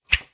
Close Combat Swing Sound 59.wav